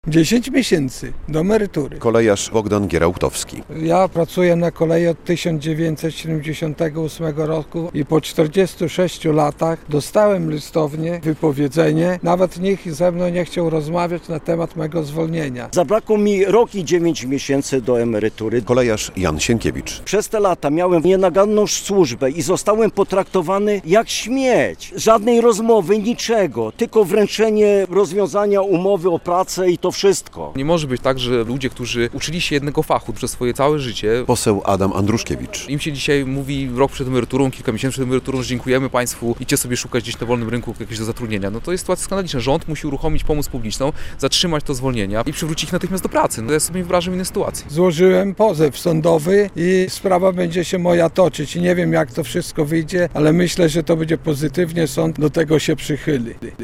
Konferencja prasowa posła Adama Andruszkiewicza, 8.10.2024, fot.
relacja